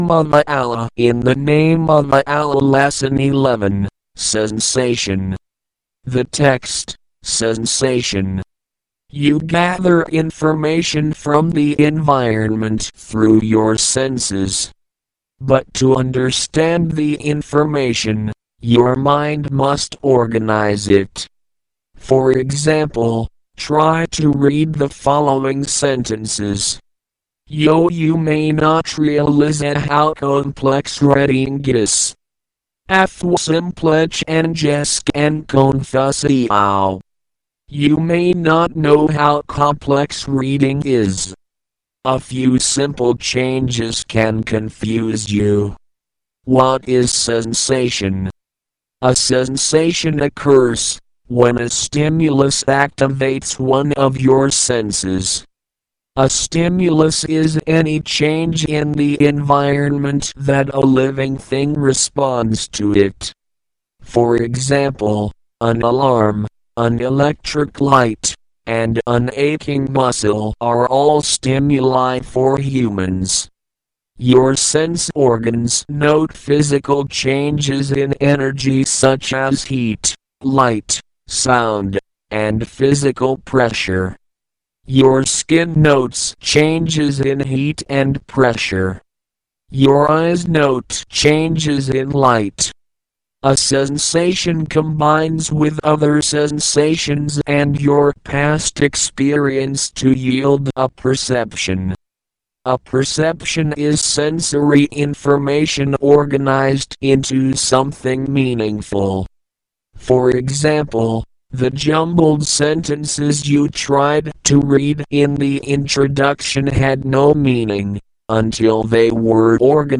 صوت درس 11 کارشناسی روانشناسی نیمه حضوری
Lesson-11-DL-Sensation.mp3